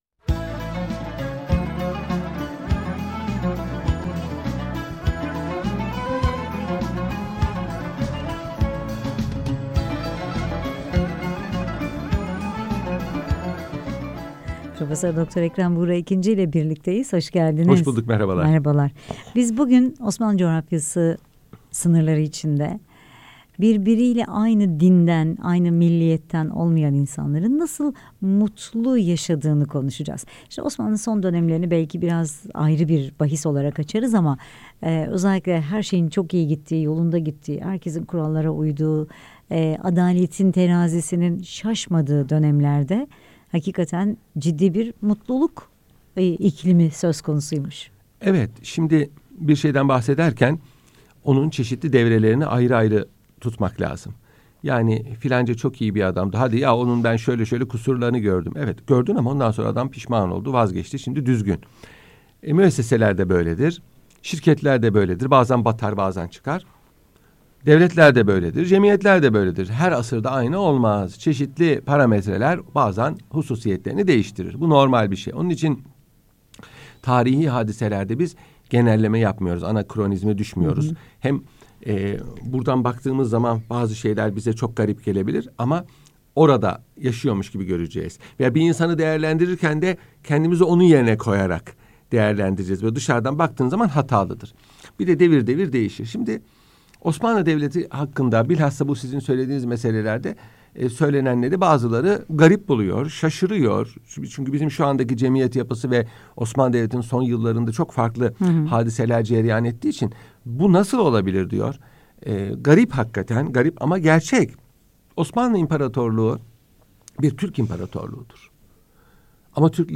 Radyo Programi - Osmanlının ötekiyle yaşama tecrübesi